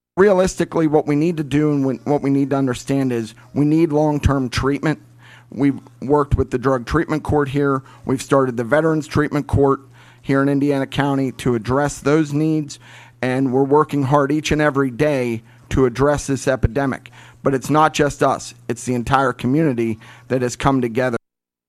This morning on WCCS AM 1160 and 101.1 FM, two debates were held concerning two positions of county government that are on the ballot for next week’s election.